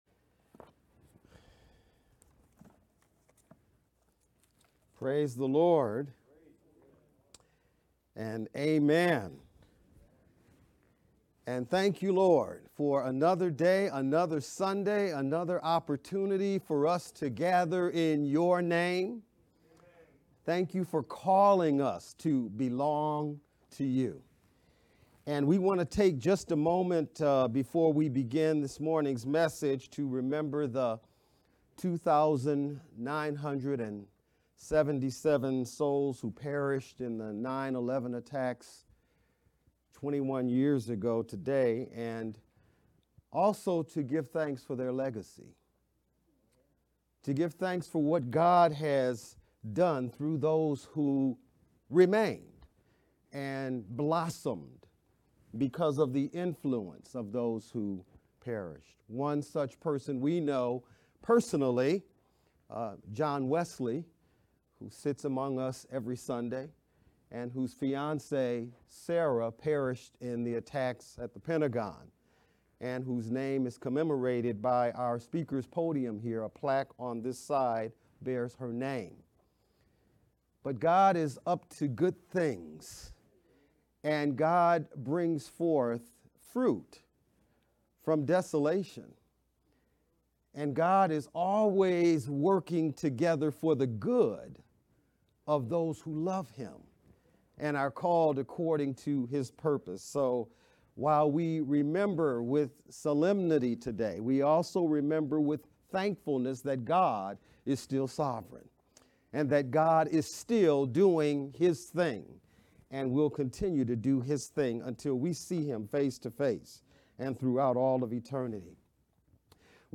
VBCC-Sermon-edited-9-11-sermon-only.mp3